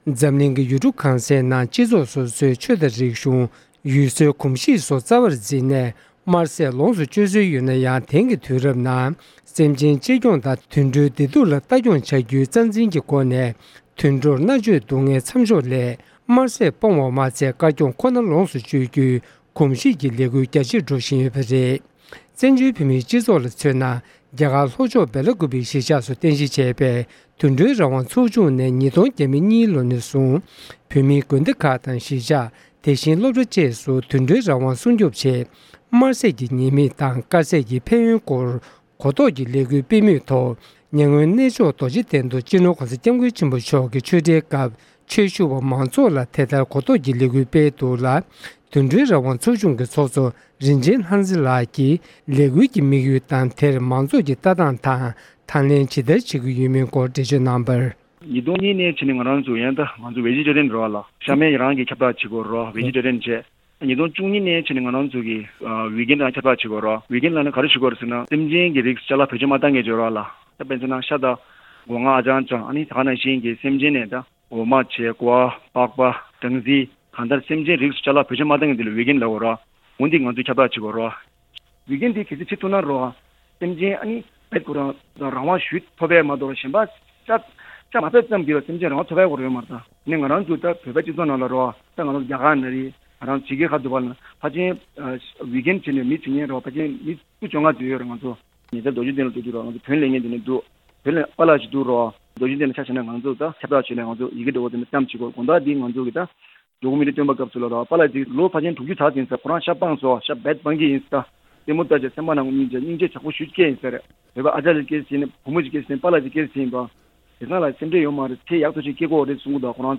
བཅར་འདྲི་ཕྱོགས་སྒྲིག་ཞུས་པ་གསན་རོགས་གནང༎